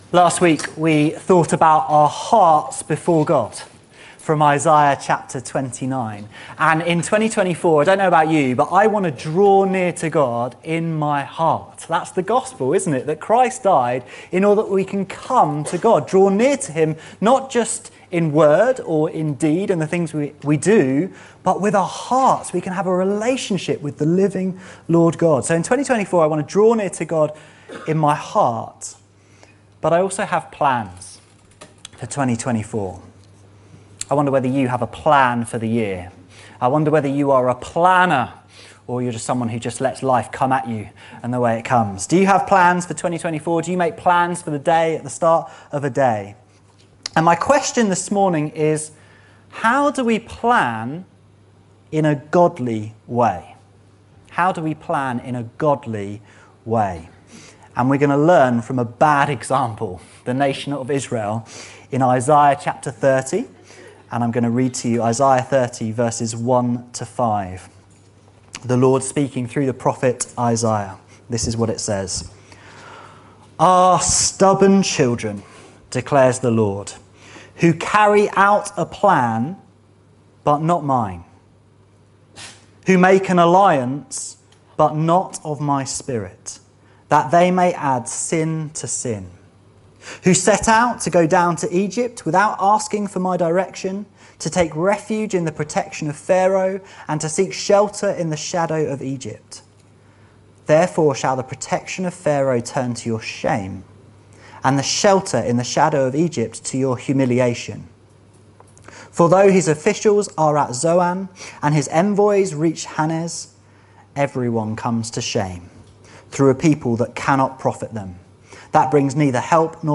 This sermon helps show us how to plan in a Godly way.